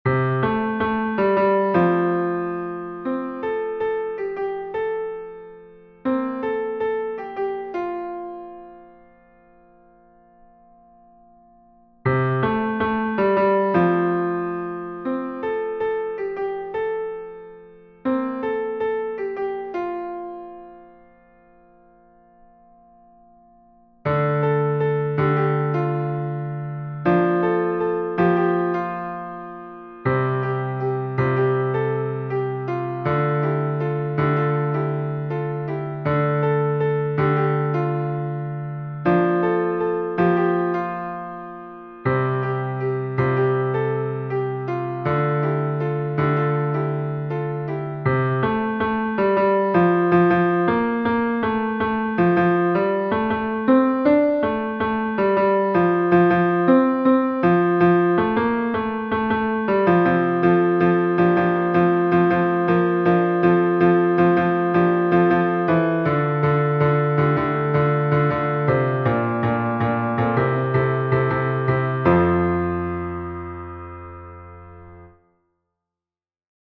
MP3 version piano